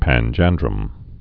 (păn-jăndrəm)